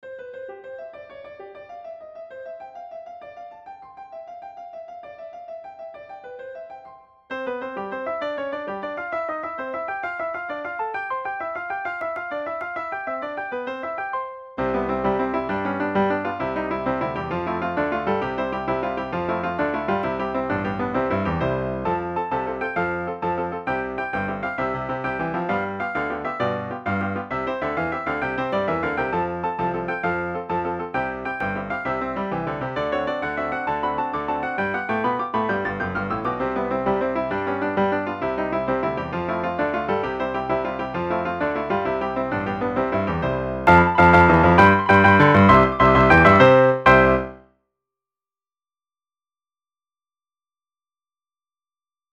FUN MUSIC ; MARCH MUSIC